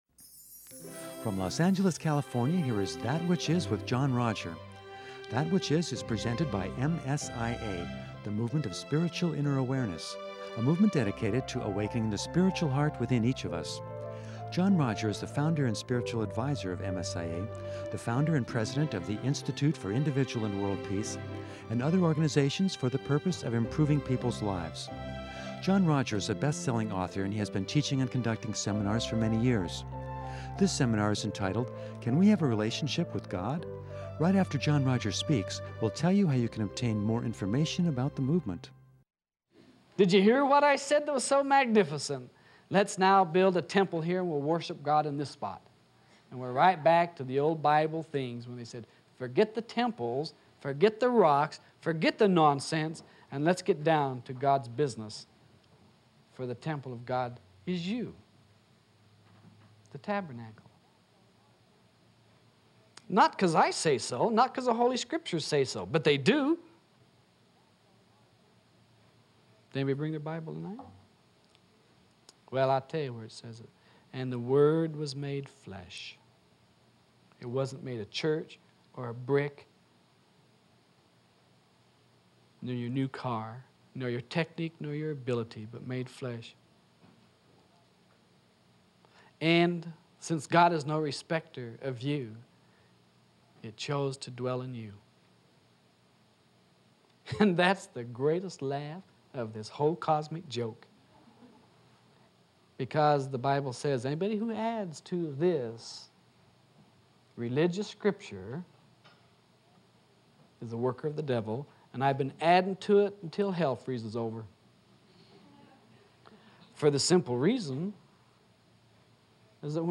This clear, entertaining, sweet and profound seminar takes another step in teaching us that to commune with God, we’d best have the courage to admit our faults.